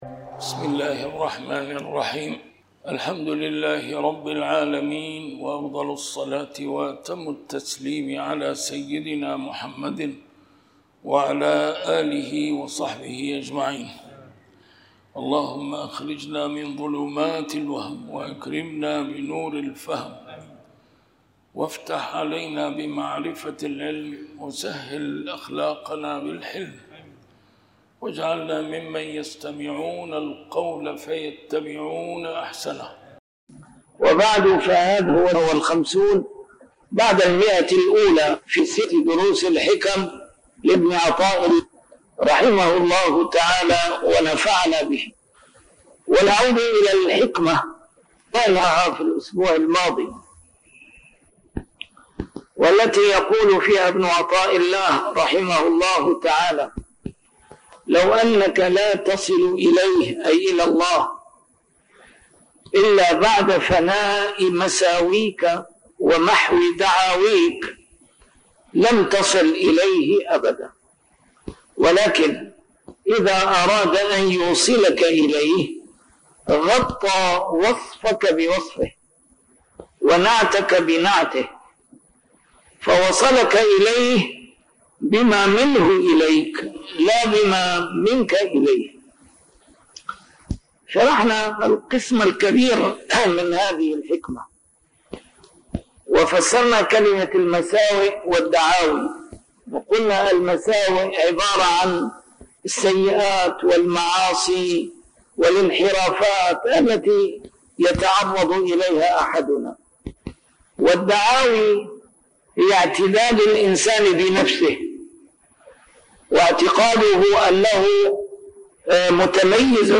A MARTYR SCHOLAR: IMAM MUHAMMAD SAEED RAMADAN AL-BOUTI - الدروس العلمية - شرح الحكم العطائية - الدرس رقم 154 شرح الحكمة 130